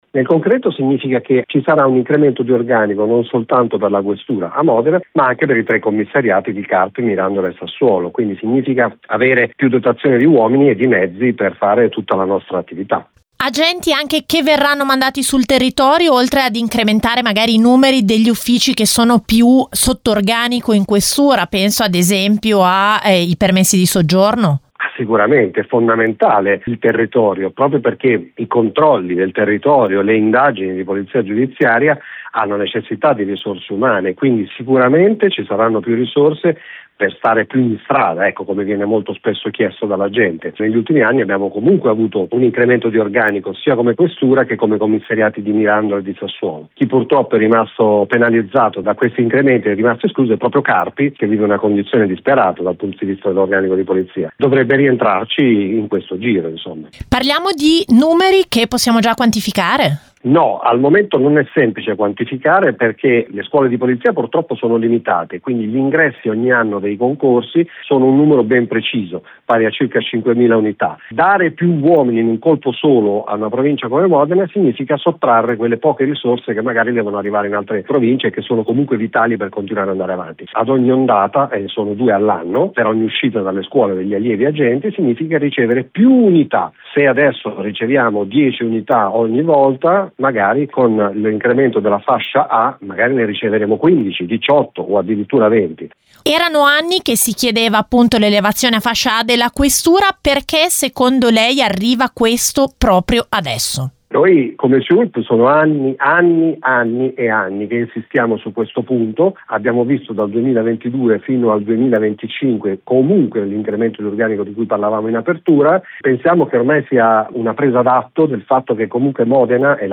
intervistato da